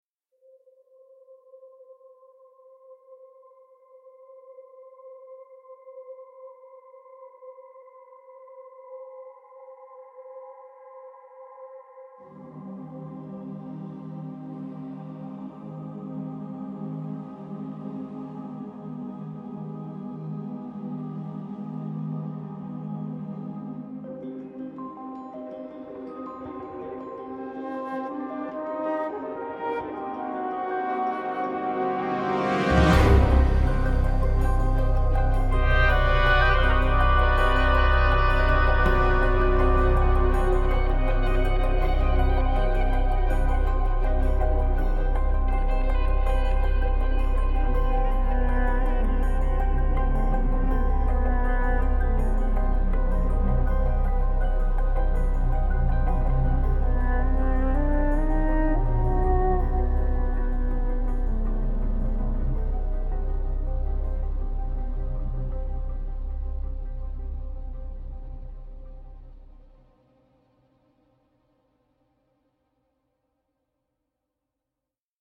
a delicate and quirky score